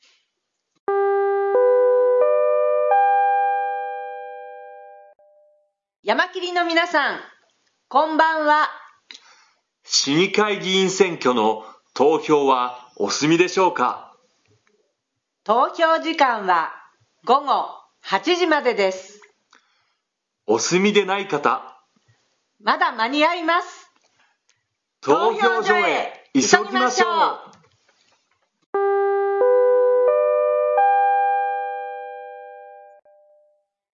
今回は、女性アナウンスに加え、男性アナウンスもあり何時もより変化に富んでいる。
当日の最終回(19時)はデュエットのアナウンスにして変化を付けてみた。